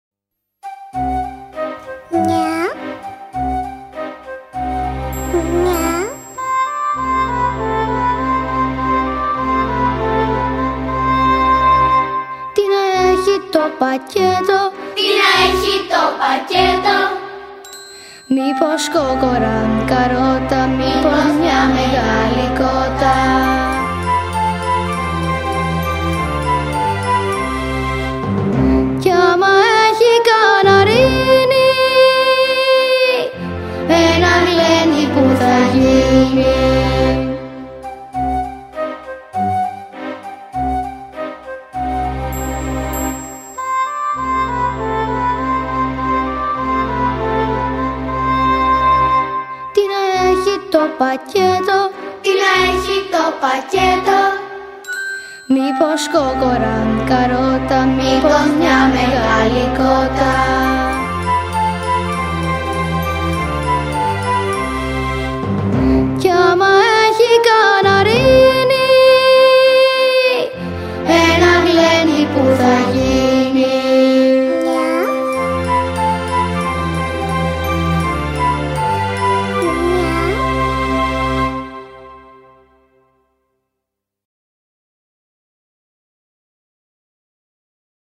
μελοποιημένα αποσπάσματα
αλλά και παιδιά δημοτικών σχολείων.